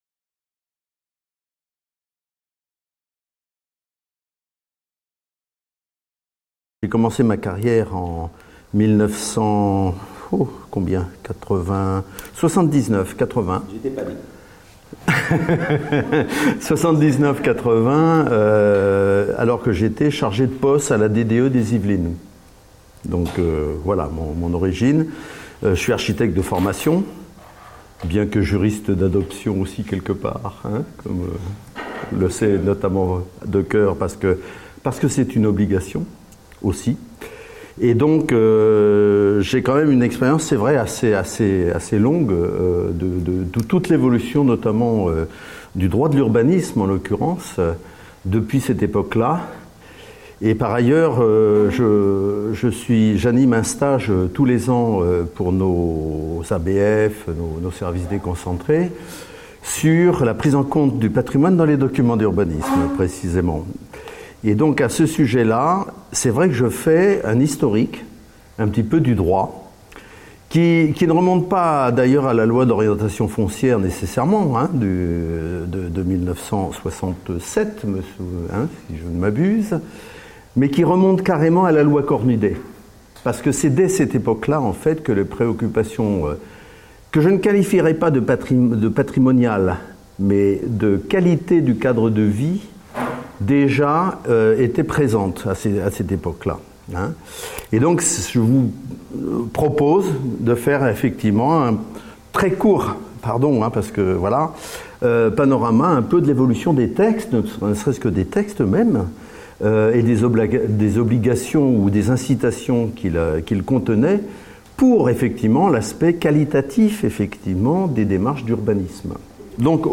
Conférence donnée dans le cadre du comité technique du programme de recherche ANR PLU PATRIMONIAL.